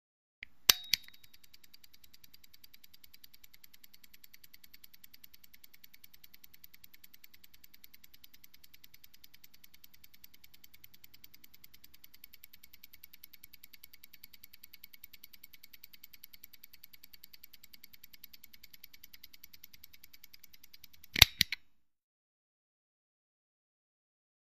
Misc. Sports Elements; Twenty Second Ticking With Start And Stop Click.